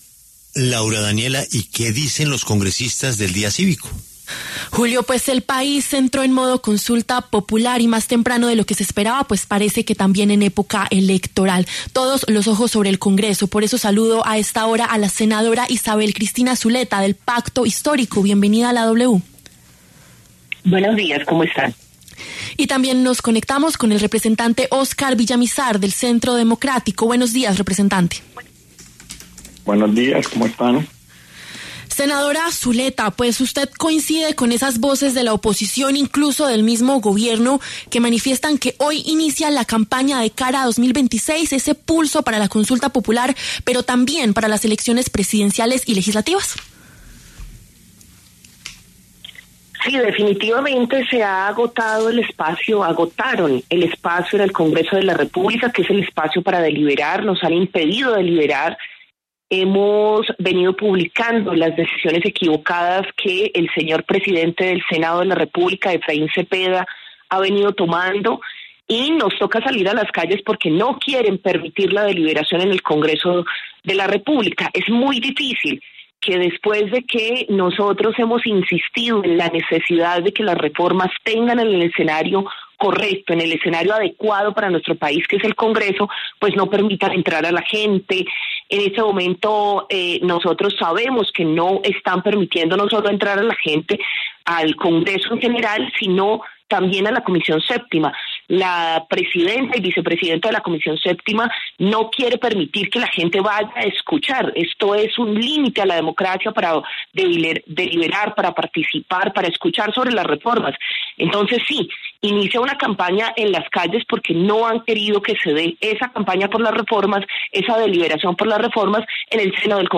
La senadora Isabel Cristina Zuleta, del Pacto Histórico, y el representante Óscar Villamizar, del Centro Democrático, pasaron por los micrófonos de La W.